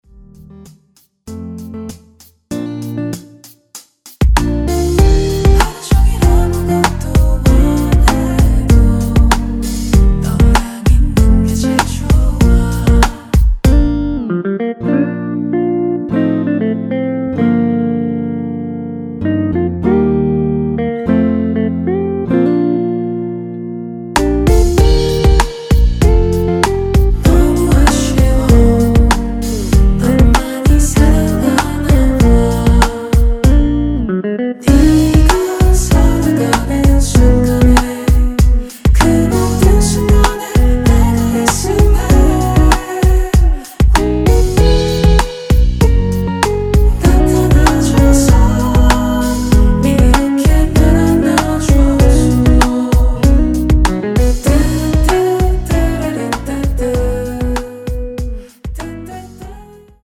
키 Bb 가수
원곡의 보컬 목소리를 MR에 약하게 넣어서 제작한 MR이며